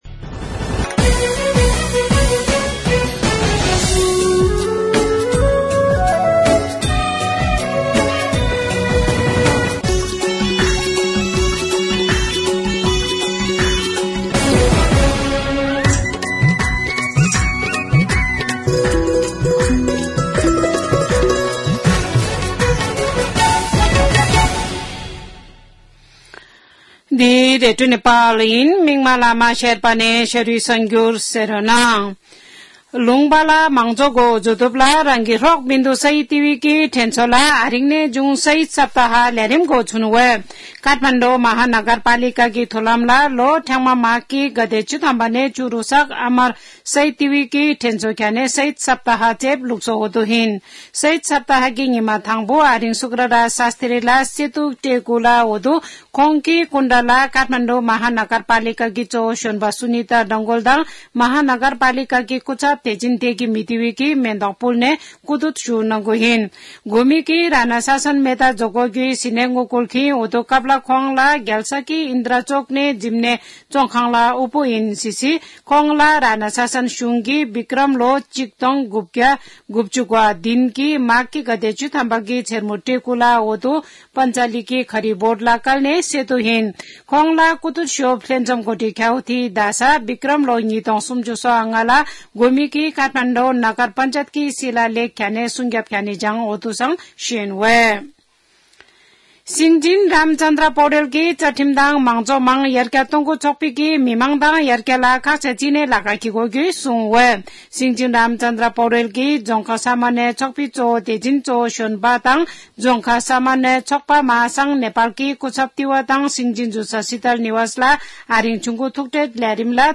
शेर्पा भाषाको समाचार : ११ माघ , २०८१
Sherpa-news-5.mp3